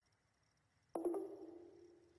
If you recieve a message, you will hear a notification Sound.
NotificationSound.mp3